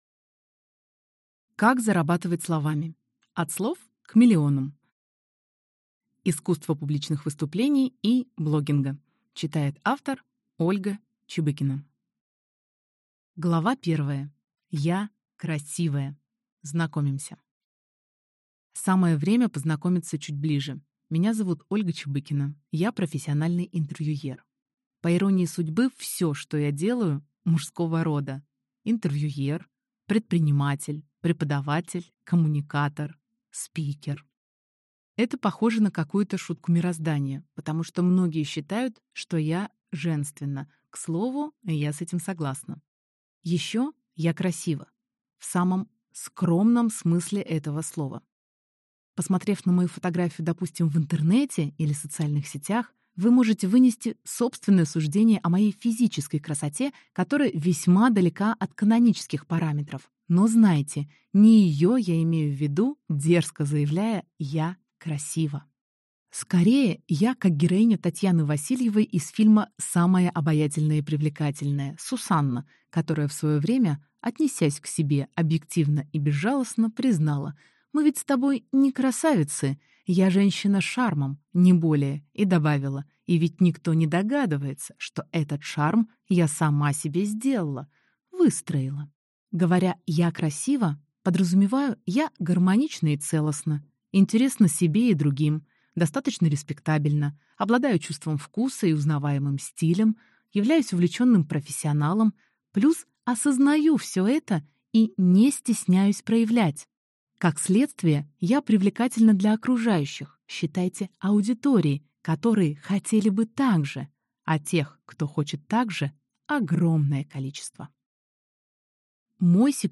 Аудиокнига Как зарабатывать словами. От слов к миллионам. Искусство публичных выступлений и блогинга | Библиотека аудиокниг